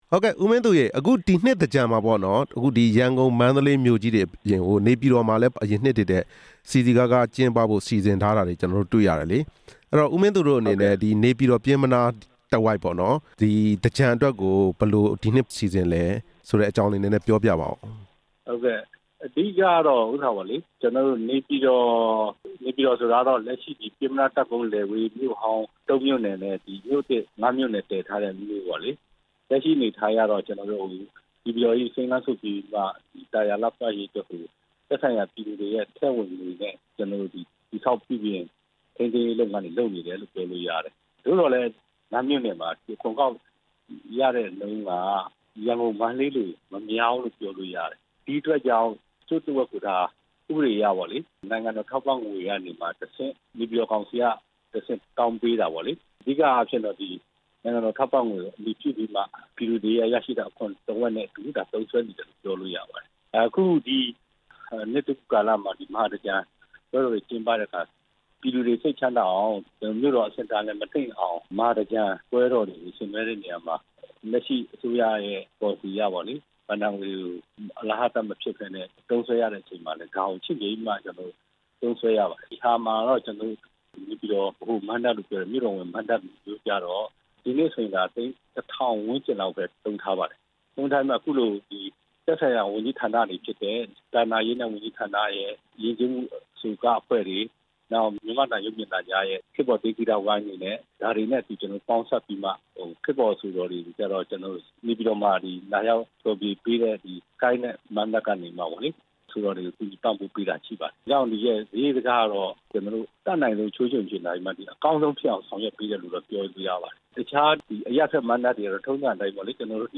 နေပြည်တော် စည်ပင်သာယာ ရေး ကော်မတီဝင် ဦးမင်းသူနဲ့ မေးမြန်းချက်